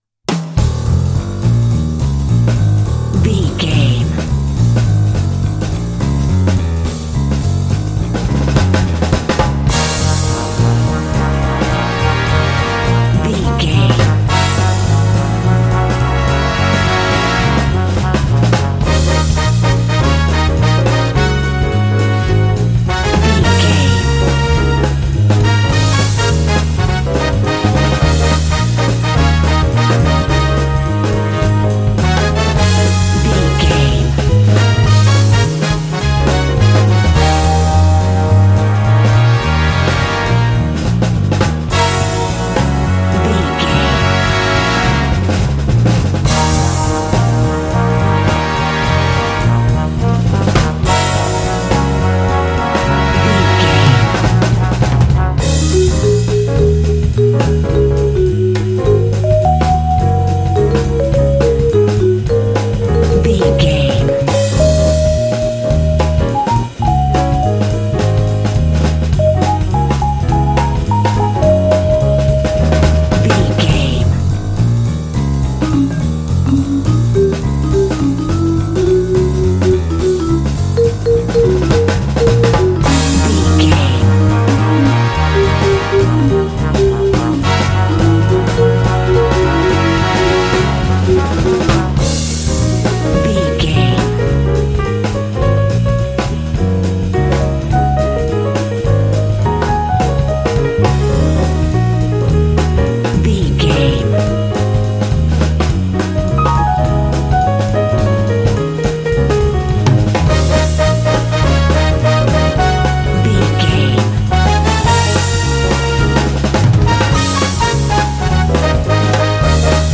Aeolian/Minor
E♭
epic
driving
energetic
cheerful/happy
groovy
lively
bass guitar
piano
drums
brass
jazz
big band